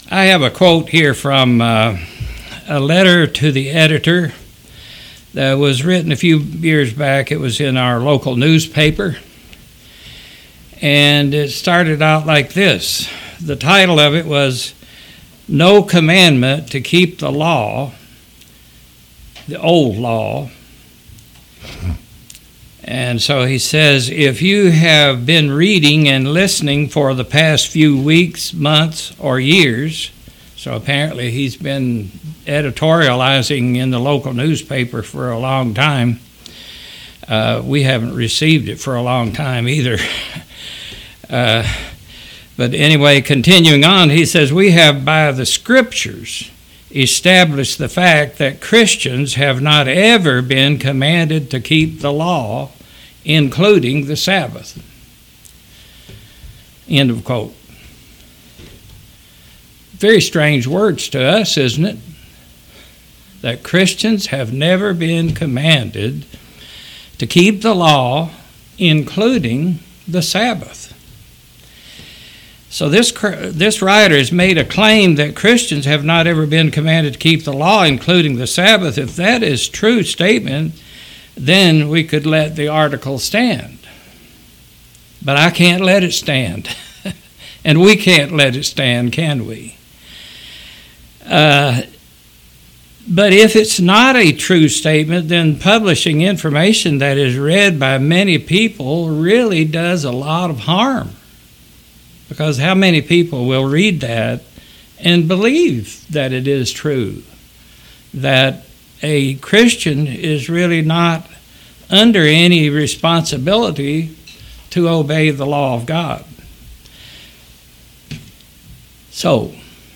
Sermons
Given in Knoxville, TN